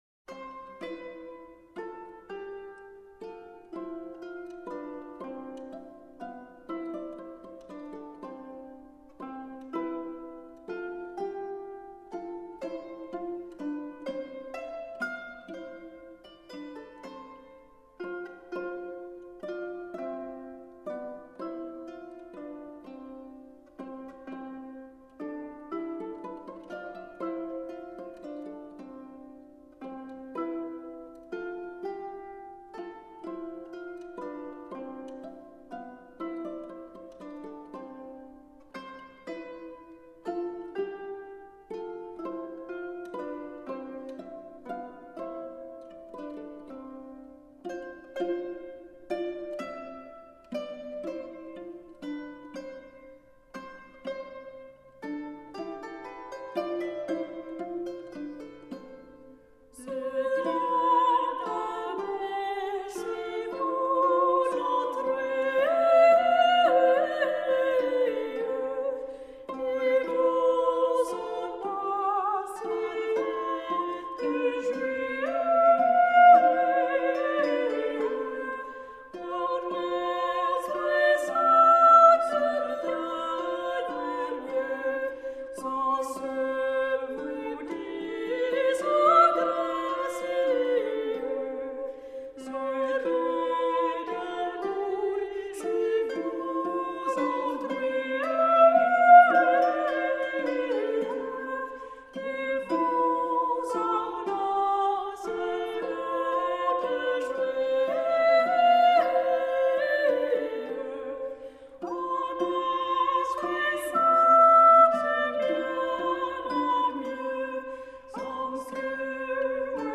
Canon